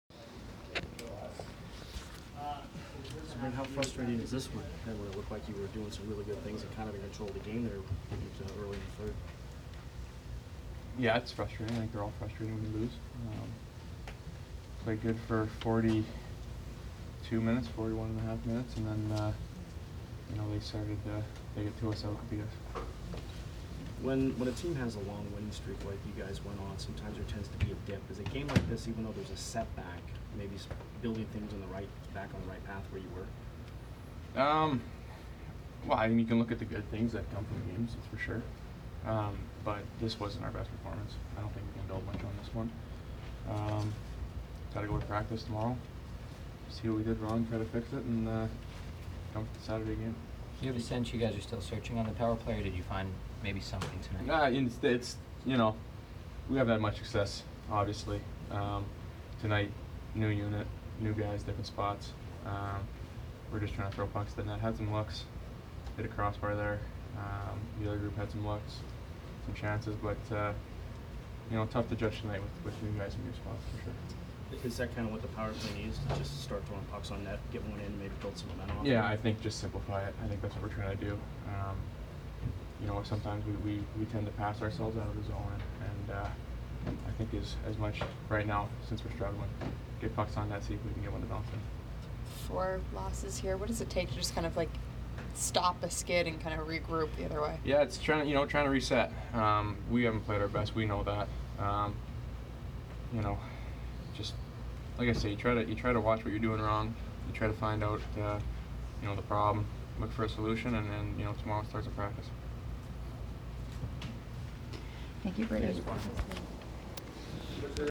Brayden Point post-game 2/27